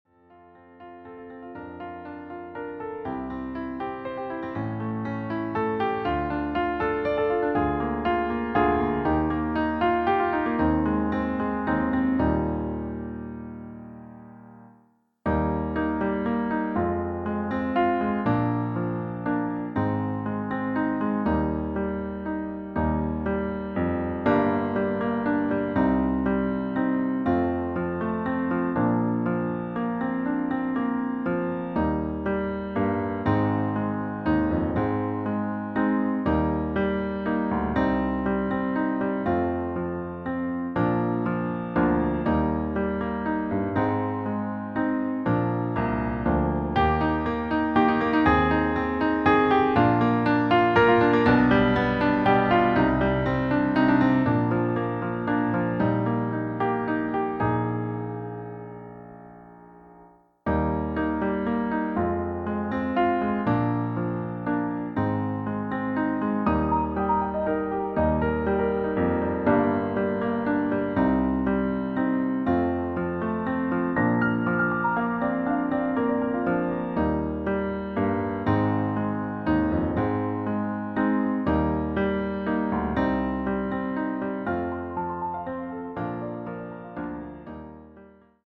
• Tonart: C, D, Eb
• Das Instrumental beinhaltet NICHT die Leadstimme
Klavier / Streicher